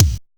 HOT KICK.wav